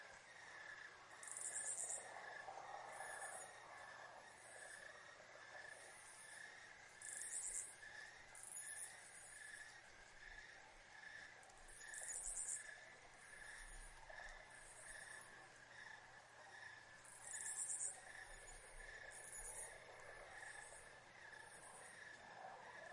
随机 " 蟋蟀 乡村田野的夜晚 漂亮的鸣叫声 + 遥远的道路
Tag: 鸣叫 蟋蟀 晚上 国家